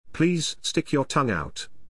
ﾌﾟﾘｰｽﾞ ｽﾃｨｯｸ ﾕｱ ﾀﾝｸﾞ ｱｳﾄ